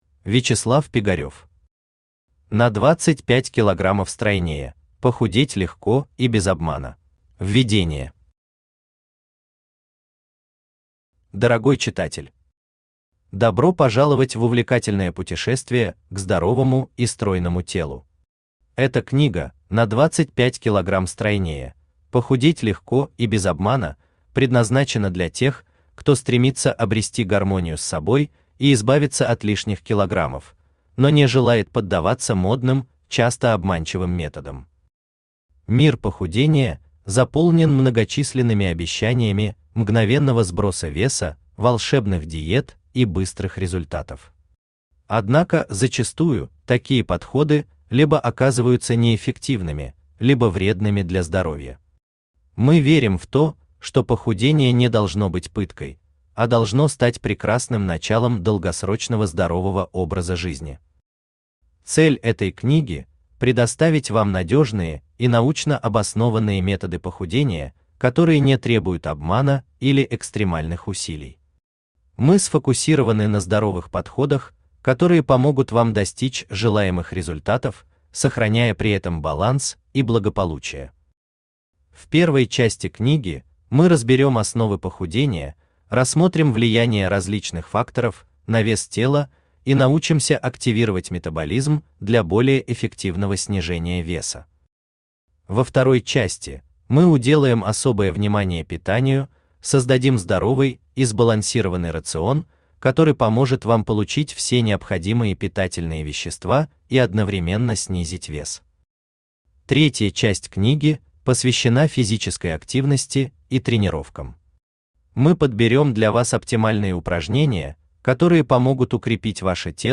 Аудиокнига На 25 килограммов стройнее: похудеть легко и без обмана | Библиотека аудиокниг
Aудиокнига На 25 килограммов стройнее: похудеть легко и без обмана Автор Вячеслав Пигарев Читает аудиокнигу Авточтец ЛитРес.